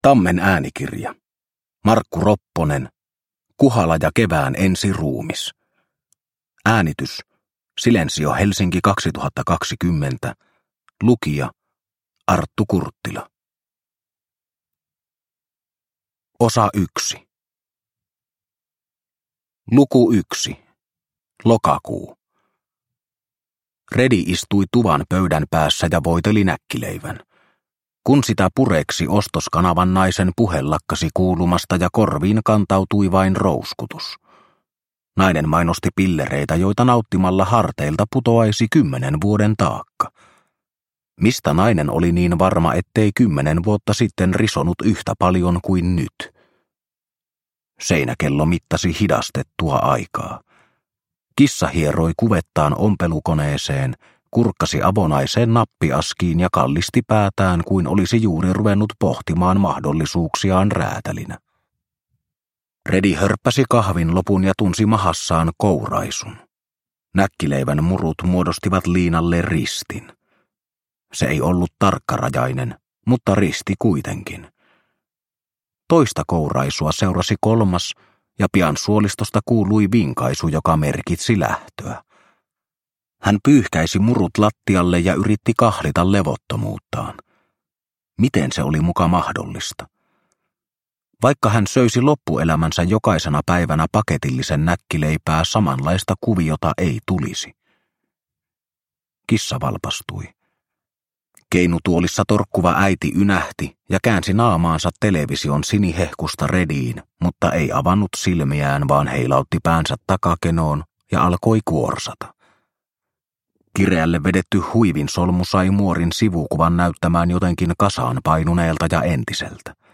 Kuhala ja kevään ensi ruumis – Ljudbok – Laddas ner